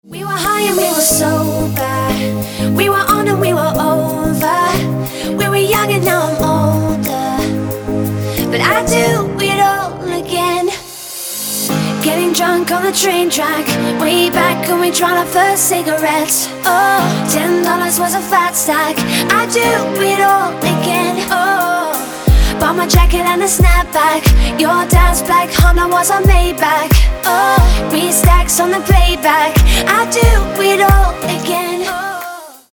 • Качество: 256, Stereo
поп
женский вокал
dance
vocal